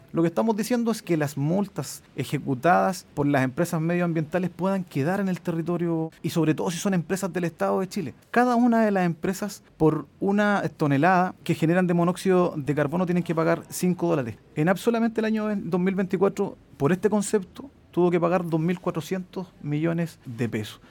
En entrevista con Radio Bío Bío, el alcalde de Hualpén, Miguel Rivera, planteó que para ejecutar la compra, se podría utilizar el dinero obtenido de las multas ambientales cursadas a ENAP.